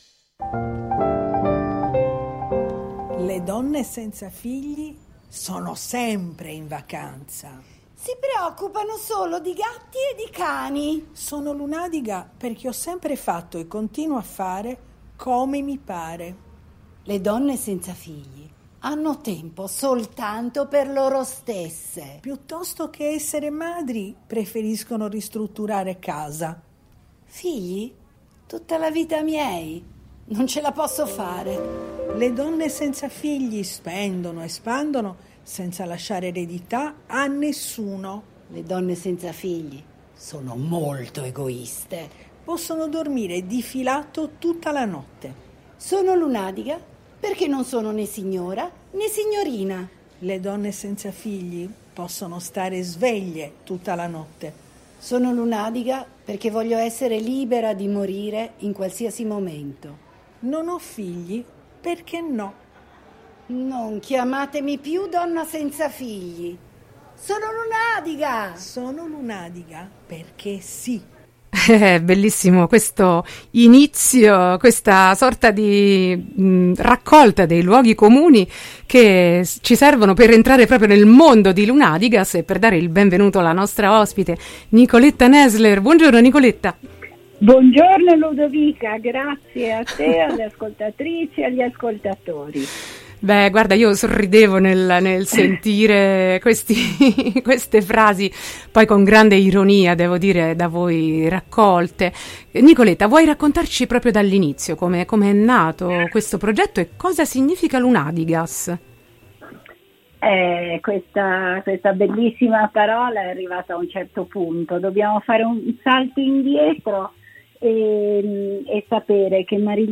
Chi sono le Lunàdigas? Intervista